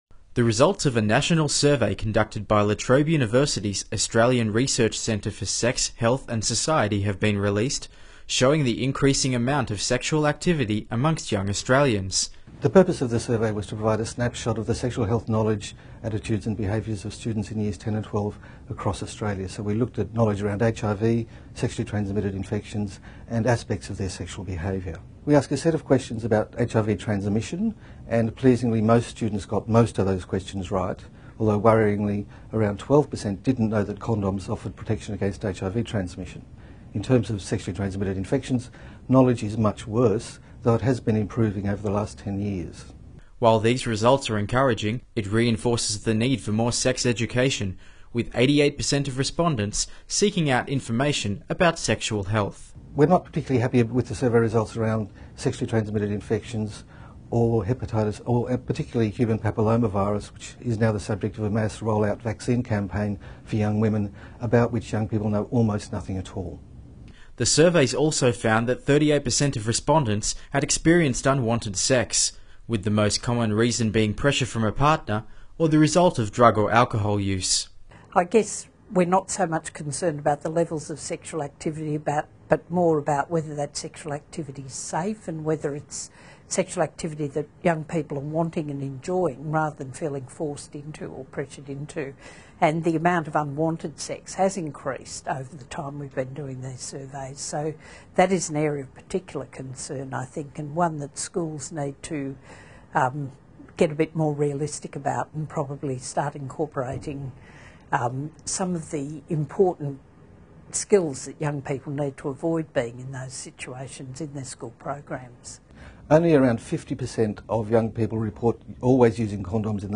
Presentation: 'Secondary School Students and Sexual Health 2008'